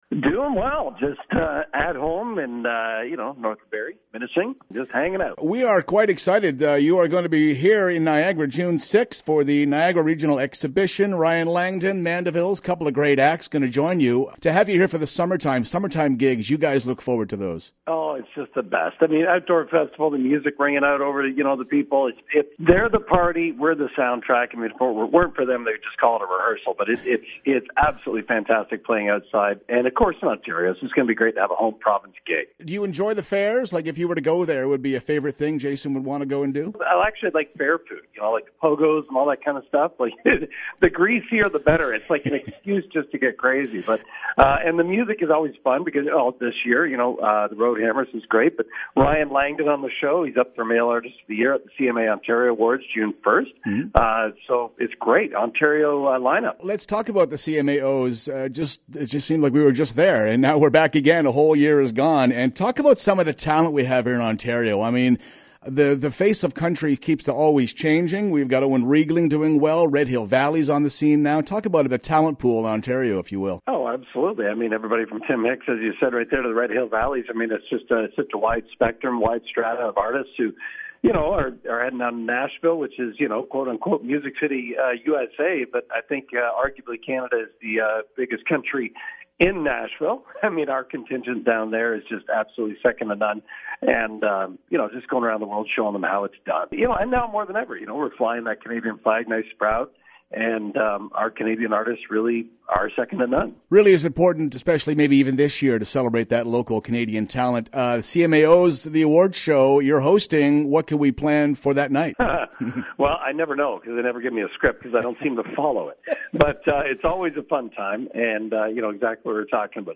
Tune in every Friday morning for weekly interviews, performances, everything LIVE!